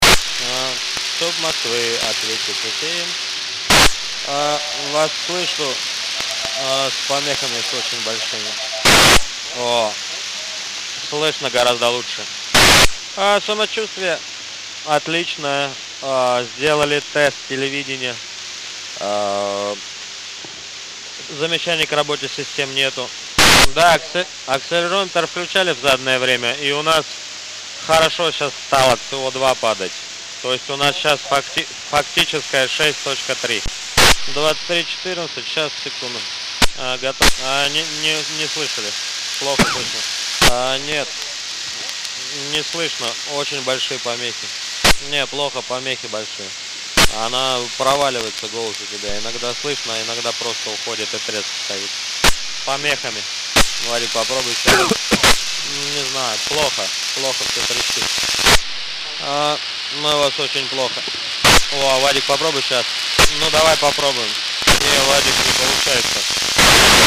I inspelningen hör man korta fräsande ljud. Det är det som är kvar sedan jag tagit bort de tysta delarna av inspelningarna då radiomottagaren bara brusar.
Man kan höra att kosmonauten tilltalar markkontrollen med "Moskva".